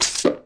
Download Pickup Whirlybird sound effect for video, games and apps.
Pickup Whirlybird Sound Effect
pickup-whirlybird.mp3